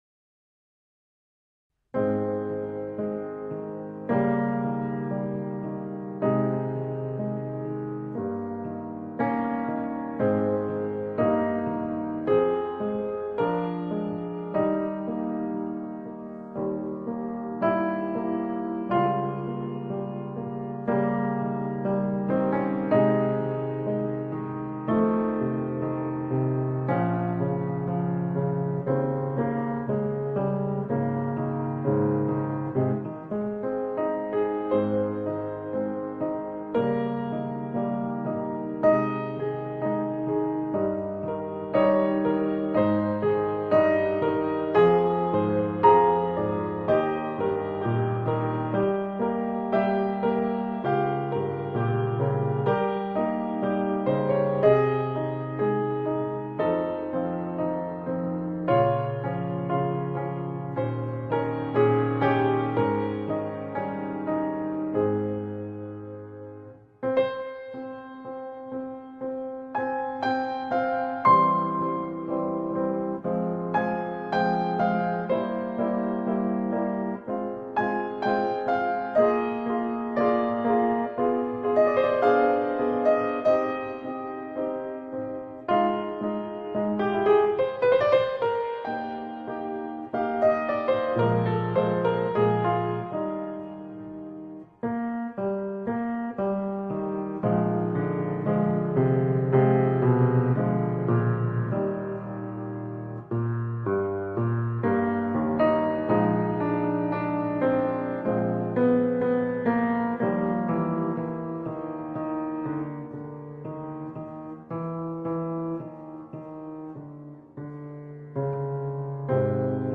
2nd Movement in A flat major
Grand Piano